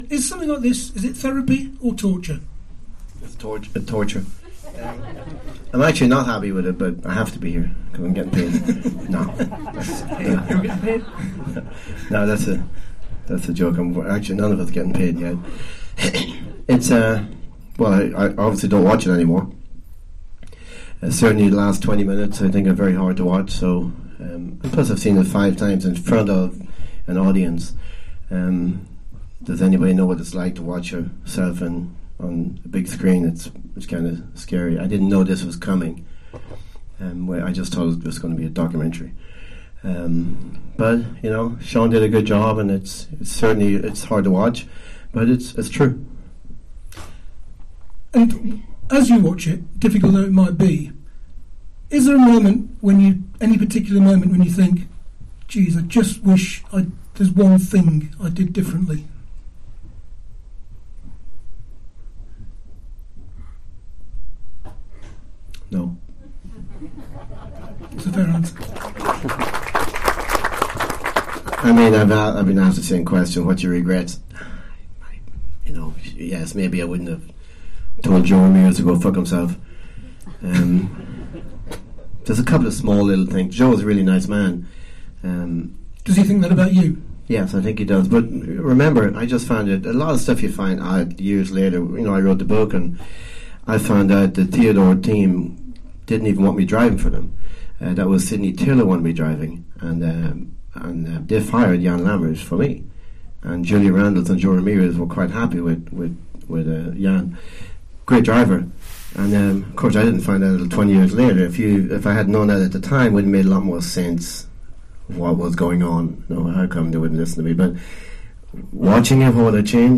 The session took place at Picturehouse Cinema Piccadilly.